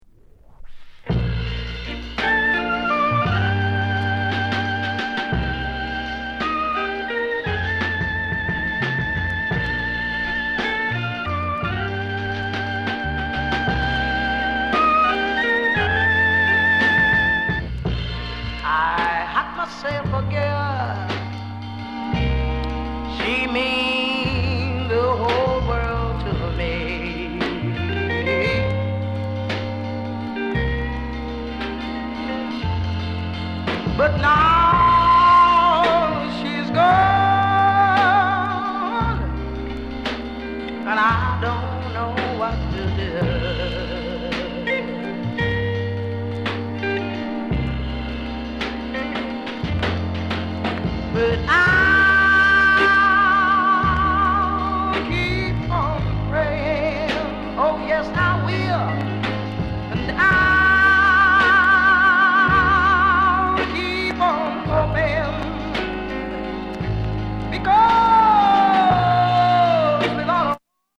NICE BALLAD